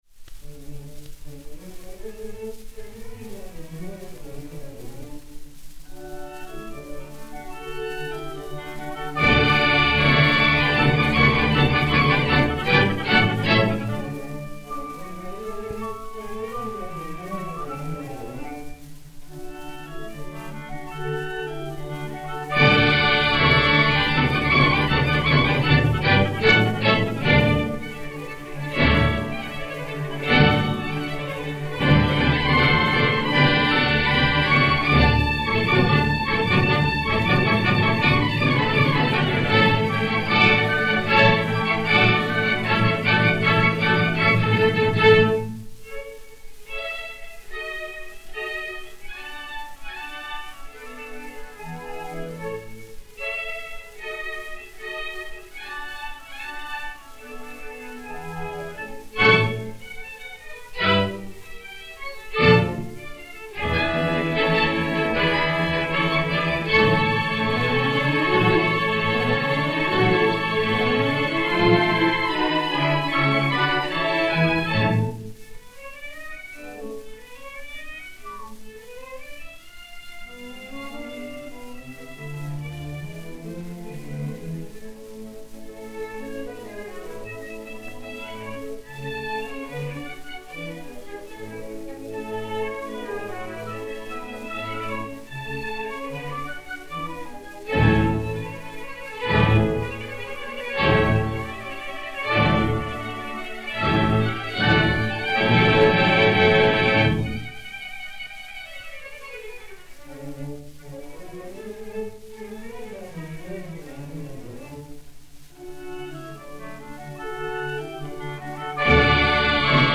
Ouverture
Orchestre de la Société des Concerts du Conservatoire dir. Philippe Gaubert